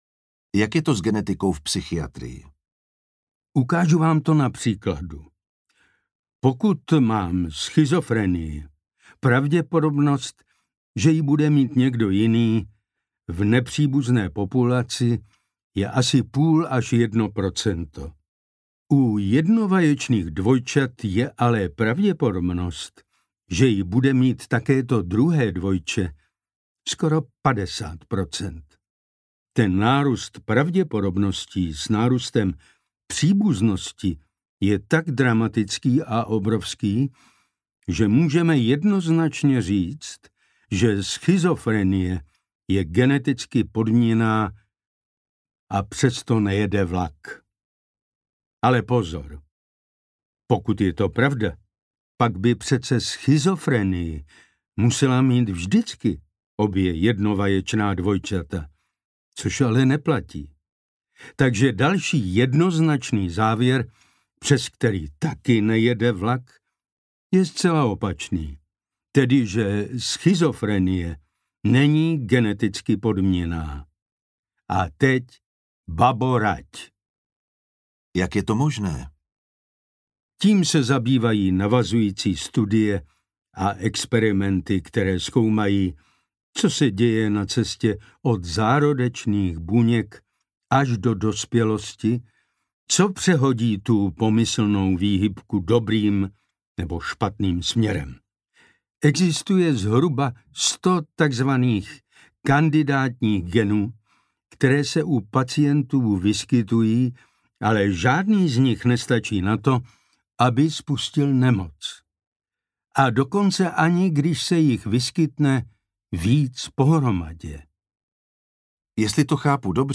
audiokniha
Čte: Jan Vondráček Petr Štěpánek